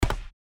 stepstone_2.wav